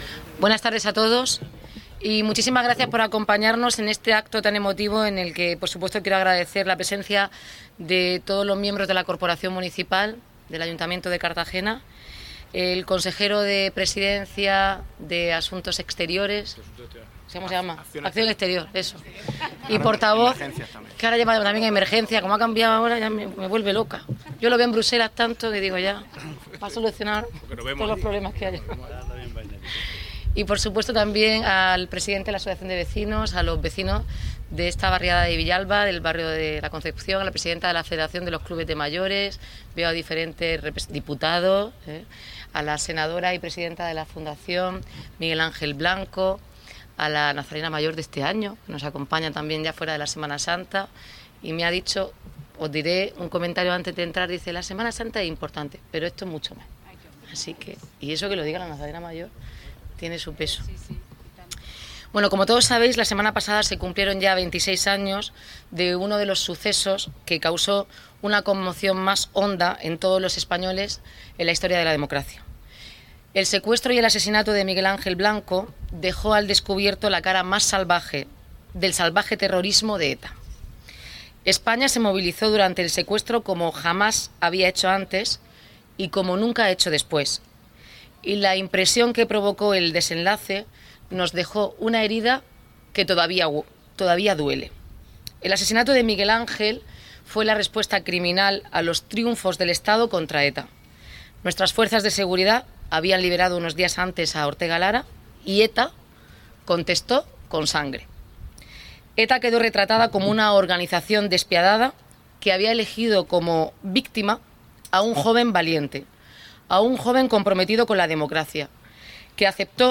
Enlace a Declaraciones de Noelia Arroyo, María del Mar Blanco y Marcos Ortuño.
El Ayuntamiento de Cartagena ha rendido este jueves, 18 de julio, un homenaje a la figura del concejal de Ermua, Miguel Ángel Blanco Garrido, cuando se cumplen 27 años de su secuestro y asesinato por la banda terrorista ETA.